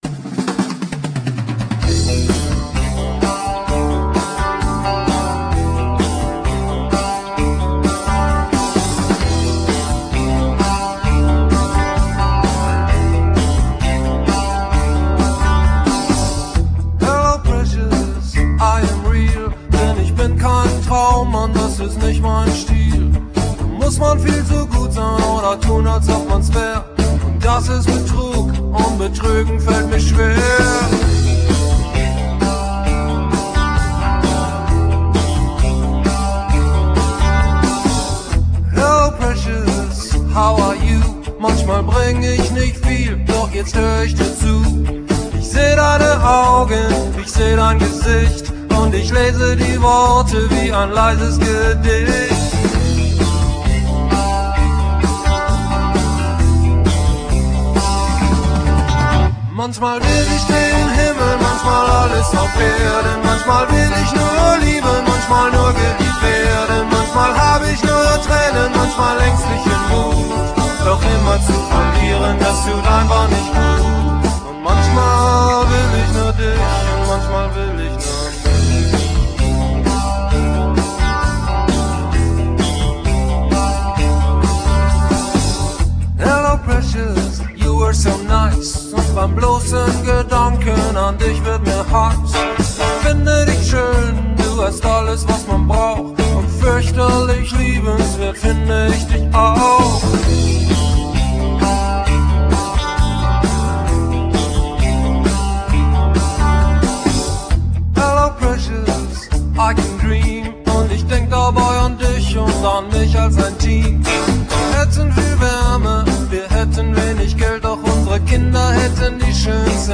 Gesang, Gitarre
Bass
Sologitarre
Schlagzeug